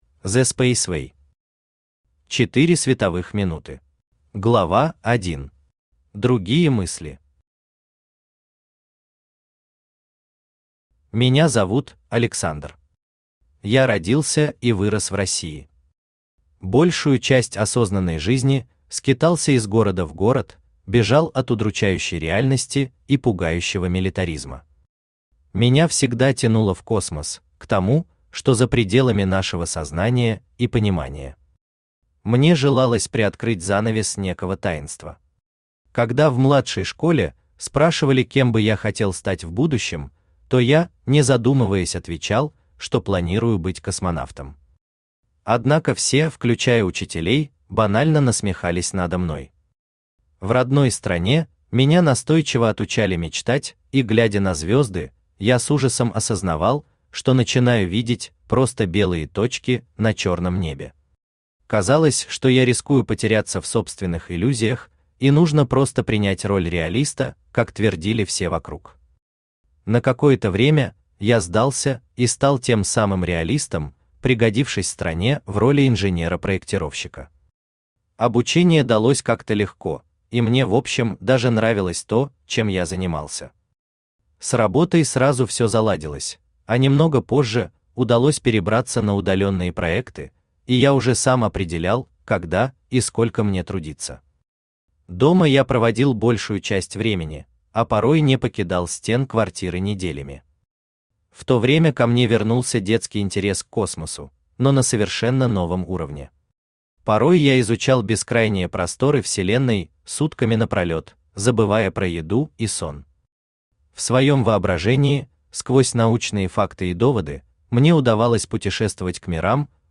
Аудиокнига Четыре световых минуты | Библиотека аудиокниг
Aудиокнига Четыре световых минуты Автор The Spaceway Читает аудиокнигу Авточтец ЛитРес.